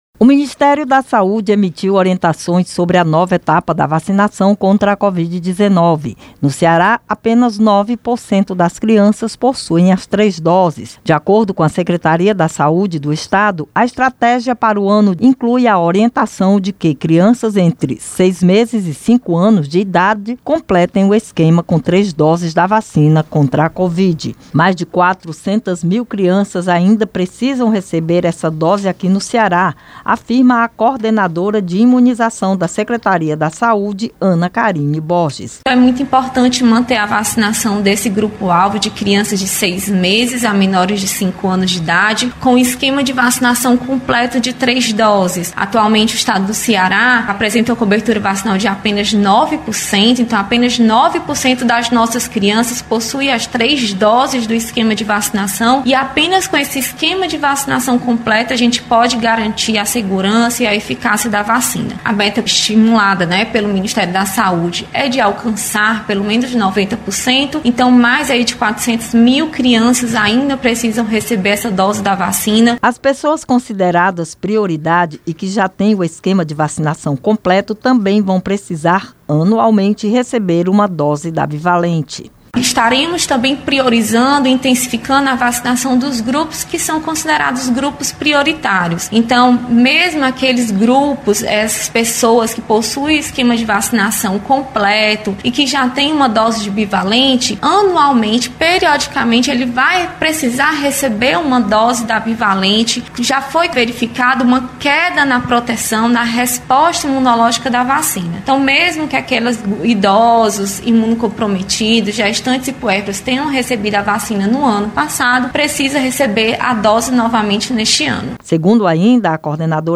Rádio ANC